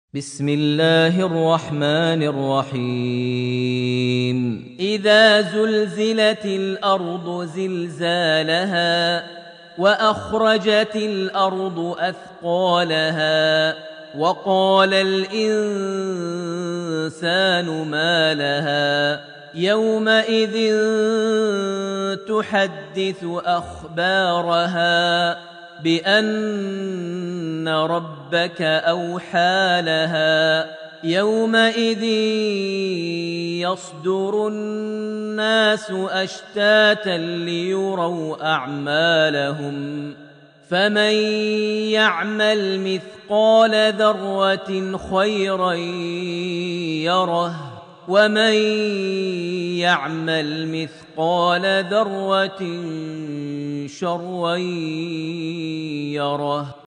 Surat Al-Zalzalah > Almushaf > Mushaf - Maher Almuaiqly Recitations